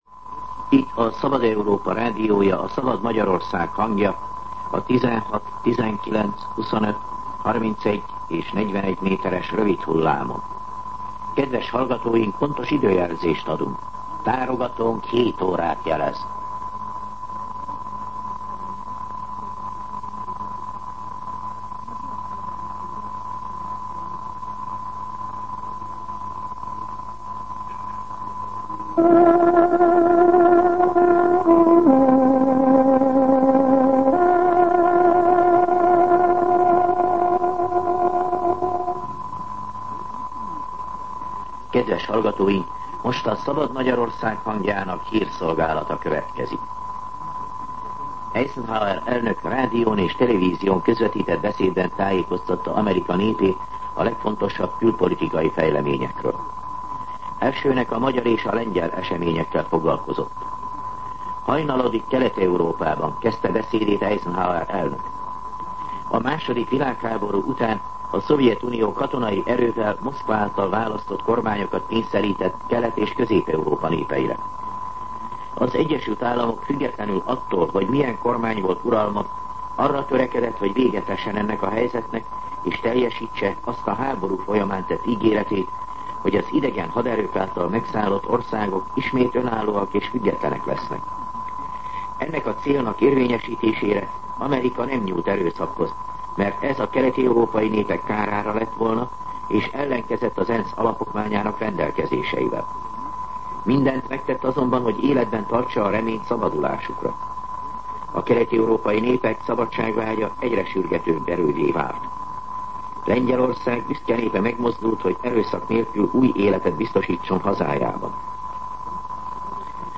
07:00 óra. Hírszolgálat